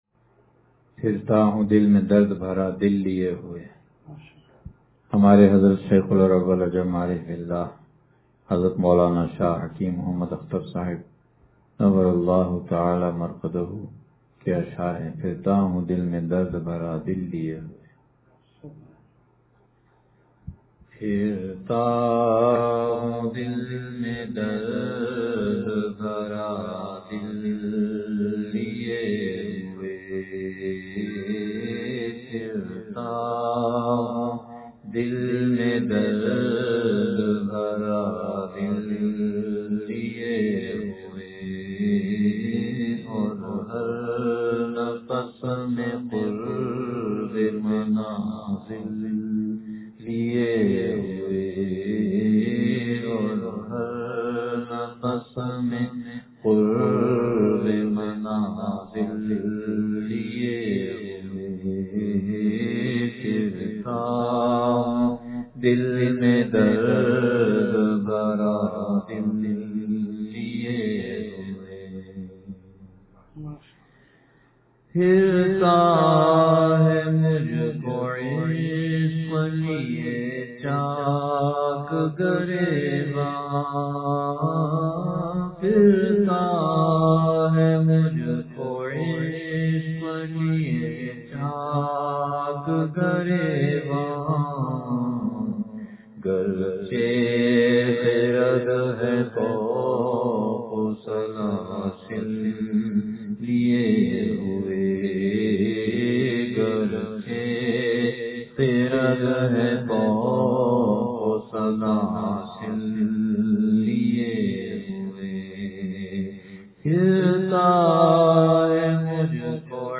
پھرتا ہوں دل میں درد بھرا دل لئے ہوئے – اصلاحی بیان